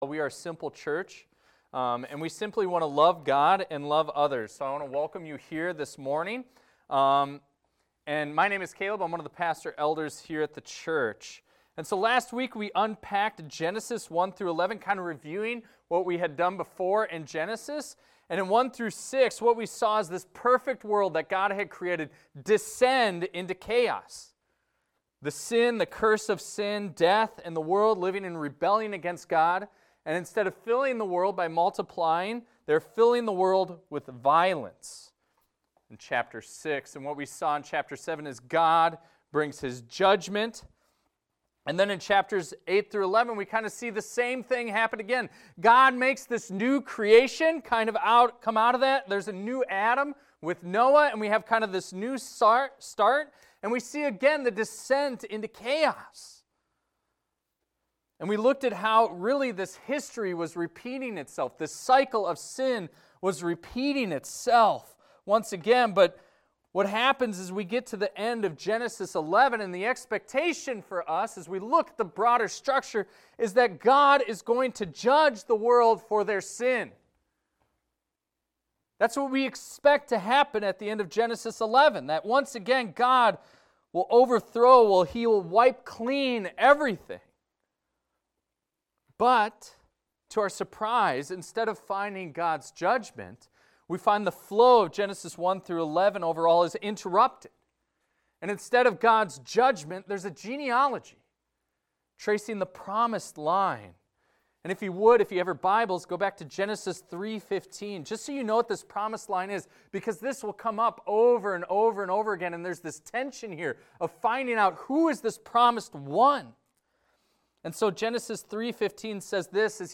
This is a recording of a sermon titled, "The Call of Abram."